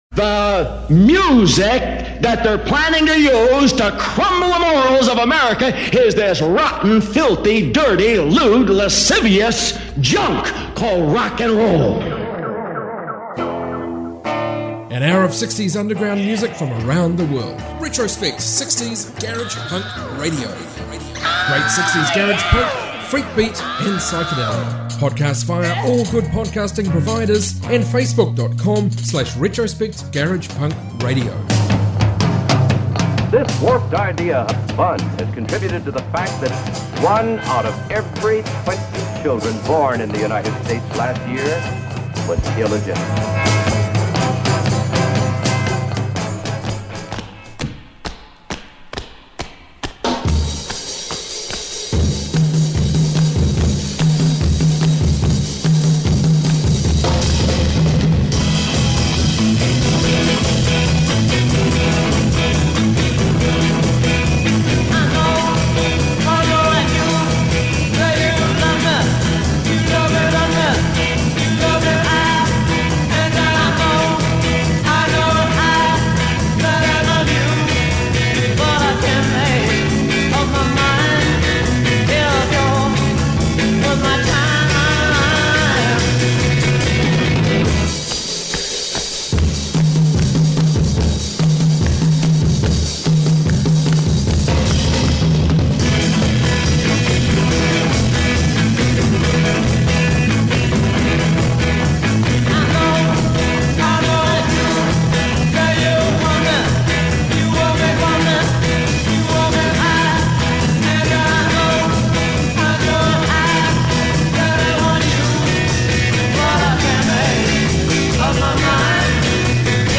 60s garage